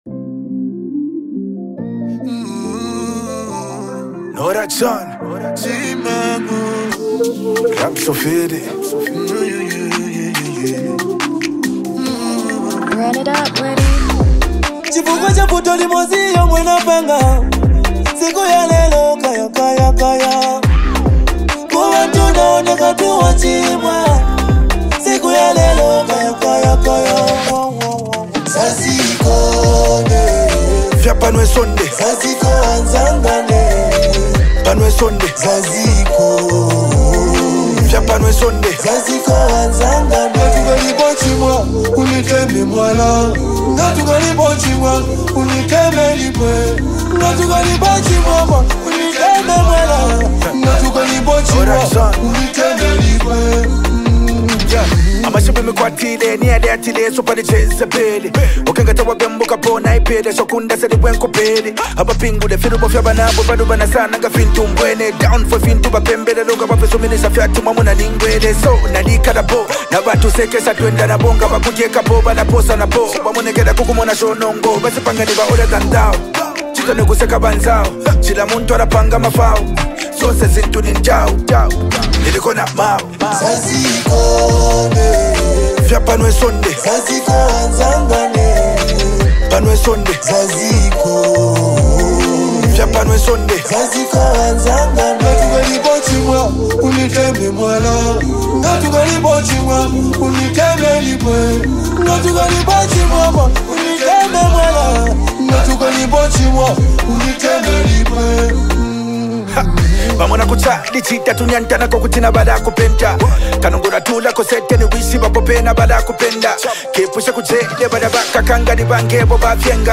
Zambian hip-hop
smooth vocals